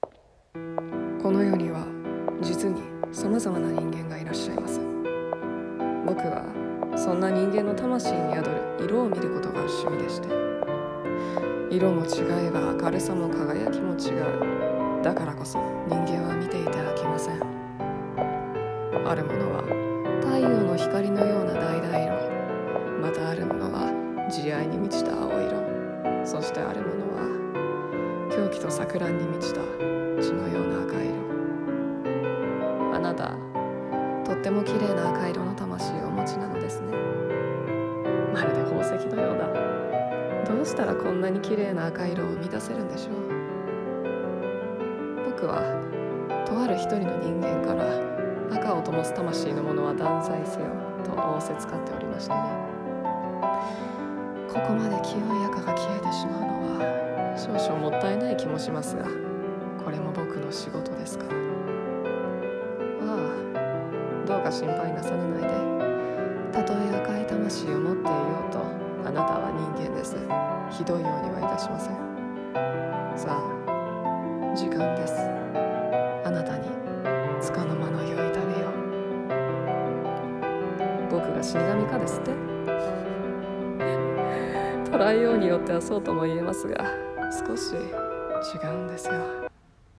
声劇 赫の処刑人